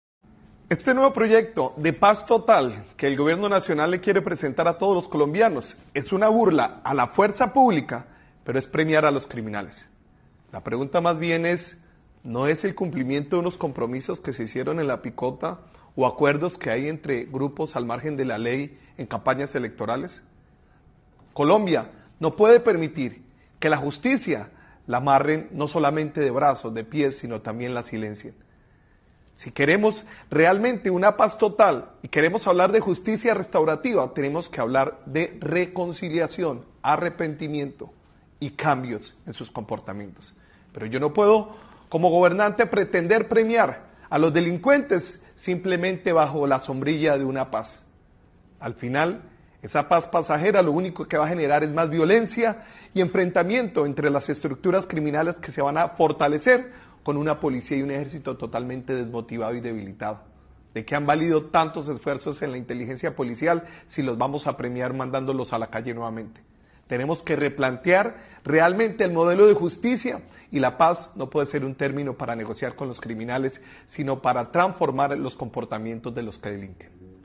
Posteriormente, durante su discurso de reconocimiento a las Fuerzas Militares y policiales previo a la conmemoración del 20 de julio que se realizó en la Plaza Cívica Luis Carlos Galán Sarmiento en Bucaramanga, Beltrán manifestó:
Discurso, Jaime Andrés Beltrán, alcalde de Bucaramanga